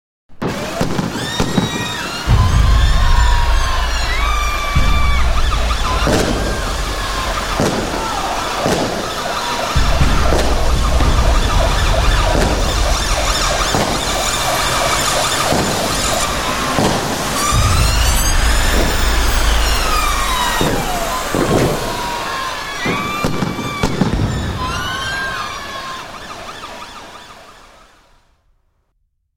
Звук атаки террористов в городе